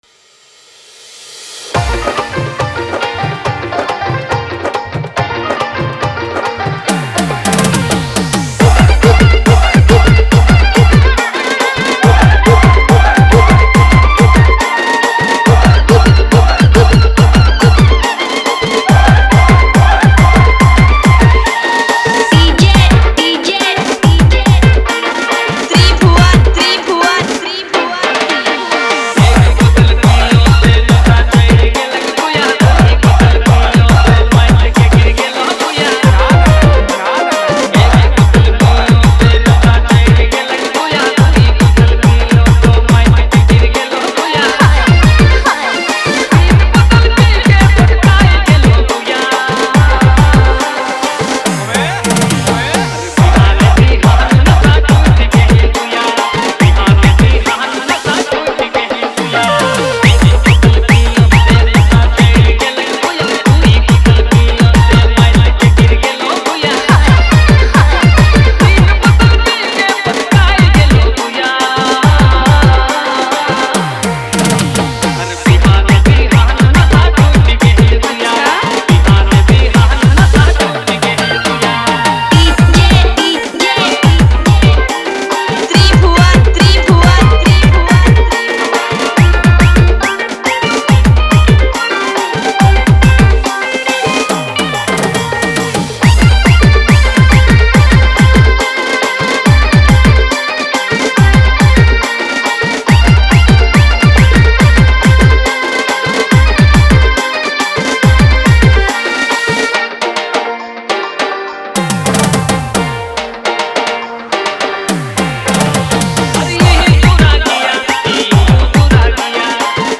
high-energy Nagpuri remix track
Nagpuri DJ hit